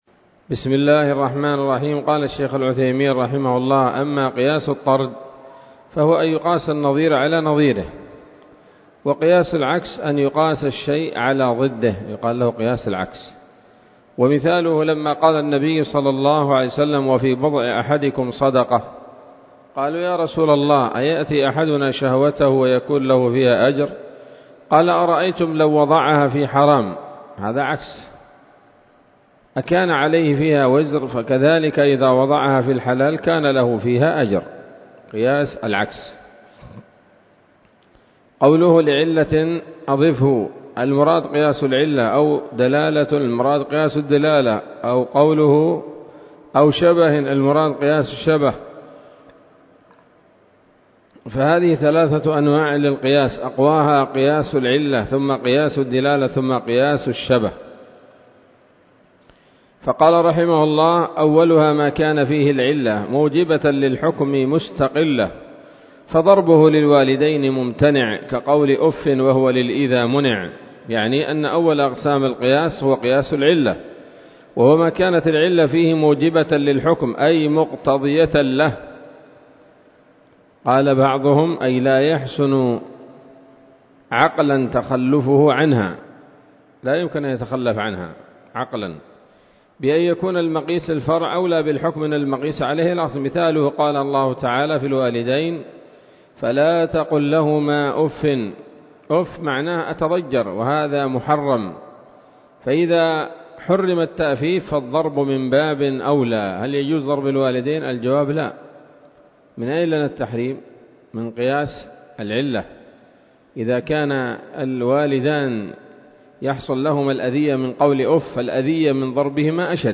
الدرس الحادي والستون من شرح نظم الورقات للعلامة العثيمين رحمه الله تعالى